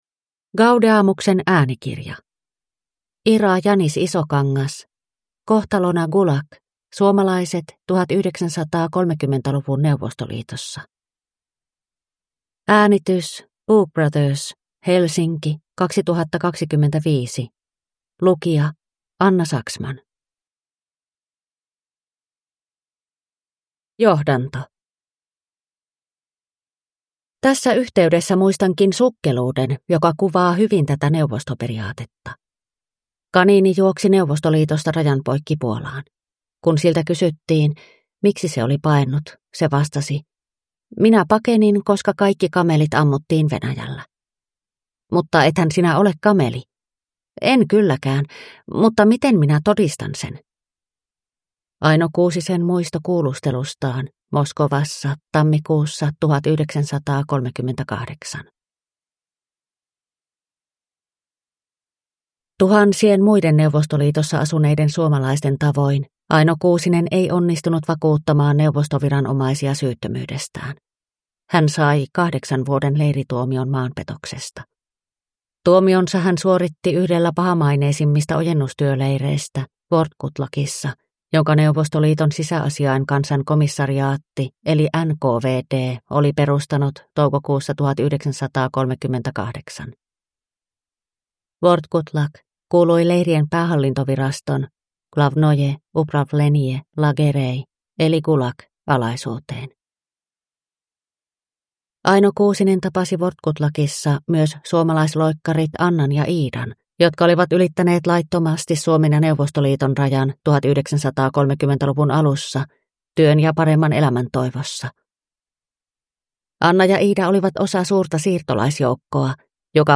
Kohtalona gulag – Ljudbok